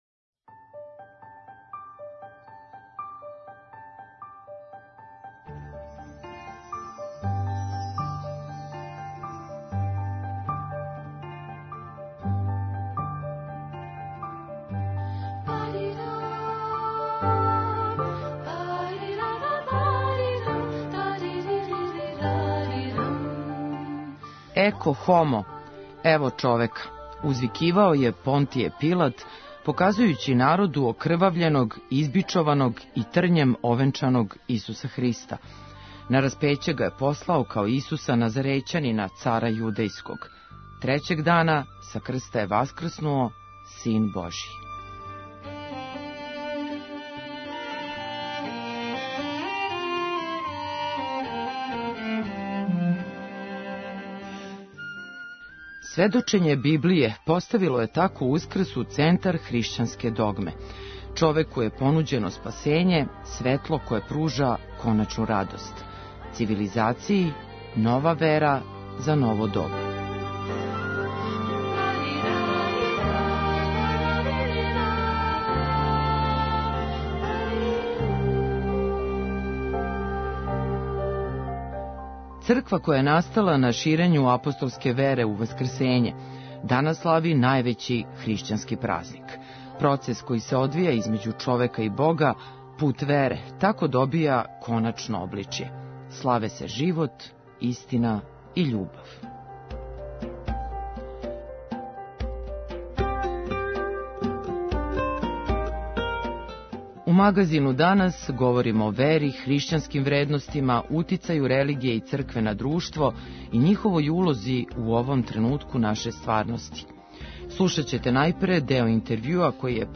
У Магазину данас говоримо о вери, хришћанским вредностима, утицају религије и цркве на друштво и њиховој улози у овом тренутку наше стварности. Слушаћете најпре део интервјуа који је патријарх српски г Порфирије дао Радио Београду.